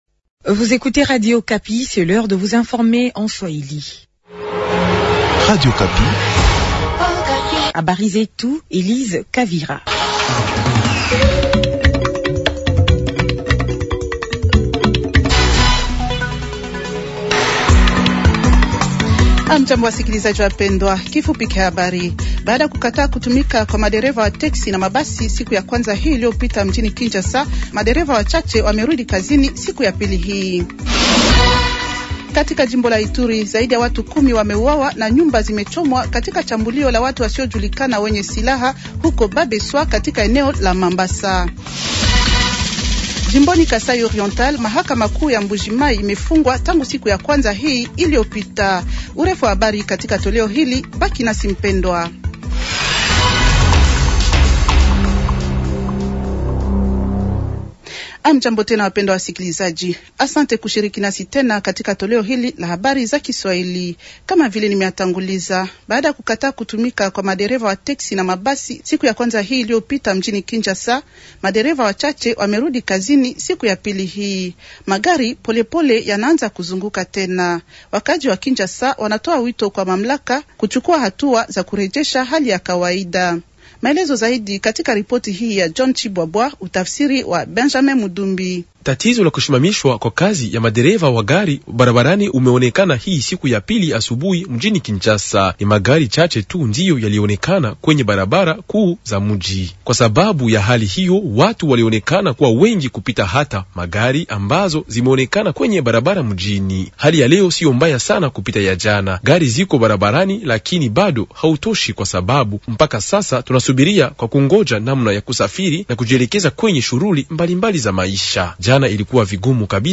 Journal de mercredi matin 180326
Habari za asubuhi siku ya tatu 180326